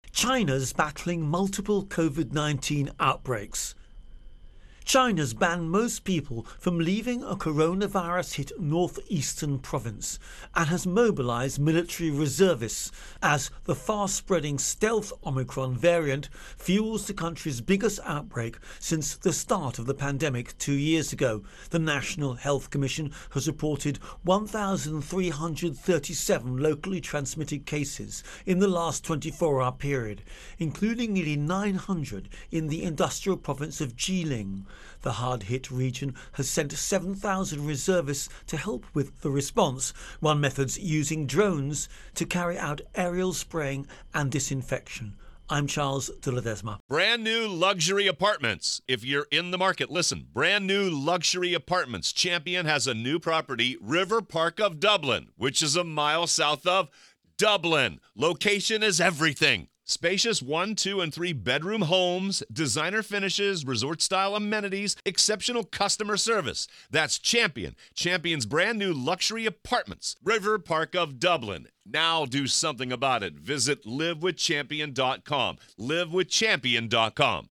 Virus Outbreak-China Intro and Voicer